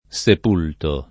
sepulto [ S ep 2 lto ]